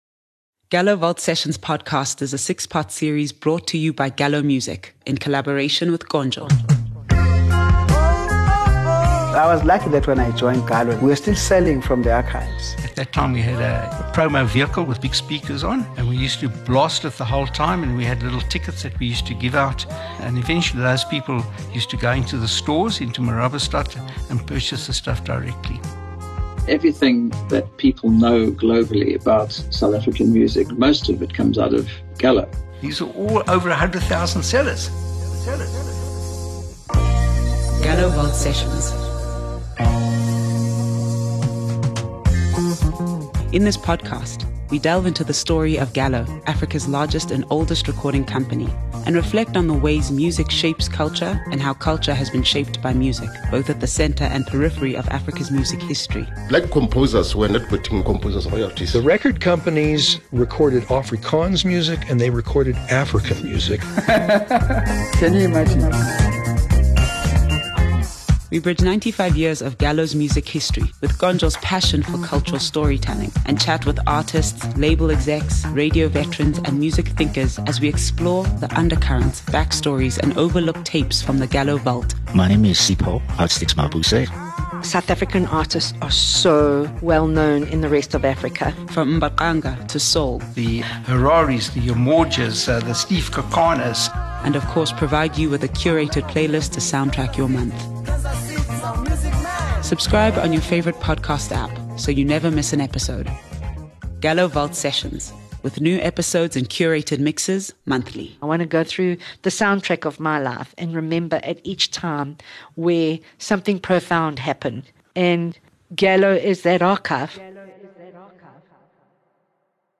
We delve into the story of Gallo Music, Africa’s largest and oldest recording company, and reflect on the ways music shapes culture, and how our culture has been shaped by music…both at the centre and periphery of Africa’s music history. We bridge 95 years of Gallo’s music history with KONJO’s passion for cultural storytelling and chat with artists, label executives, radio veterans and music thinkers as we explore the undercurrents, backstories and overlooked tapes from the Gallo Vault; from mbaqanga to soul, and also, provide you with a curated playlist to soundtrack each month.